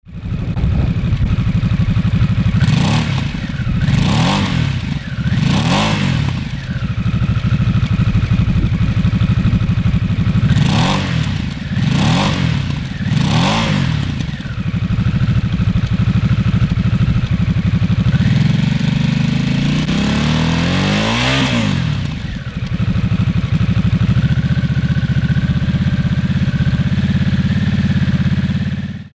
S&S Cycle - Stock - Nightster